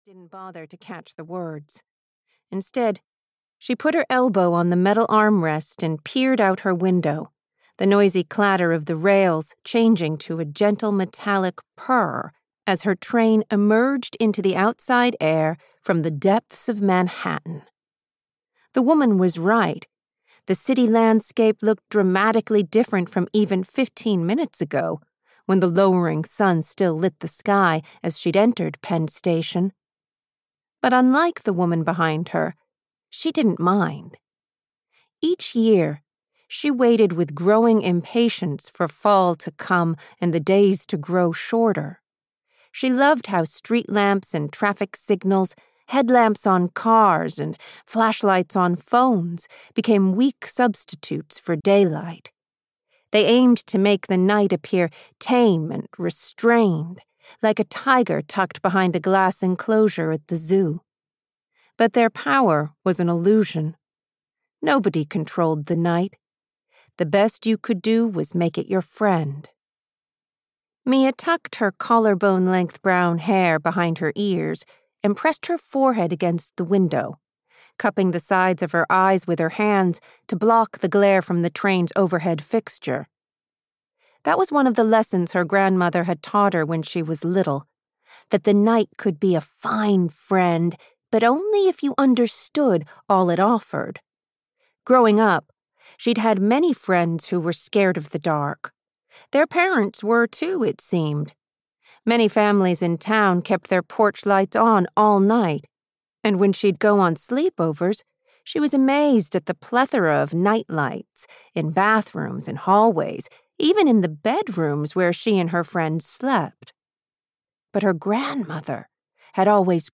Secrets of the Italian Island (EN) audiokniha
Ukázka z knihy